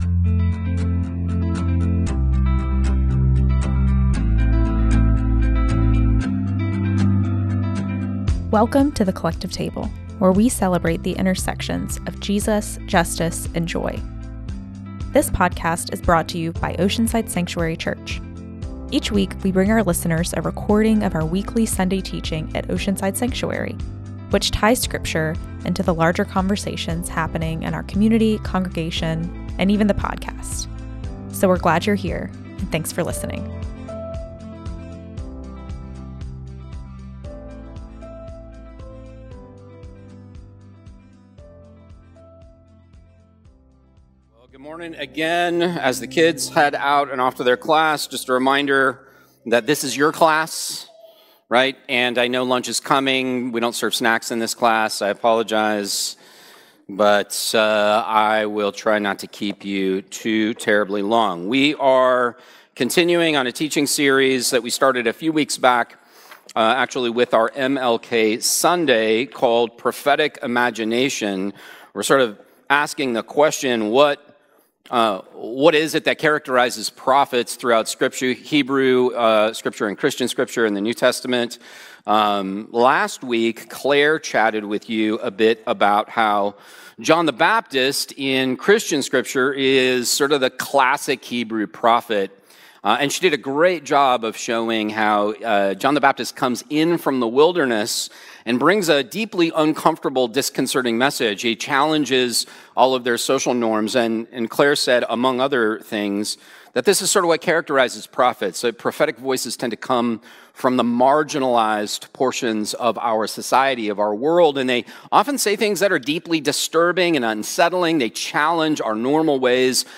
Each week, we bring our listeners a recording of our weekly Sunday teaching at Oceanside Sanctuary, which ties scripture into the larger conversations happening in our community, congregation and podcast.
This teaching was recorded on Sunday, February 2nd, 2025 at The Oceanside Sanctuary Church (OSC) in Oceanside, CA.